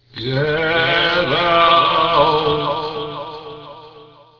voice-over-transylvania.wav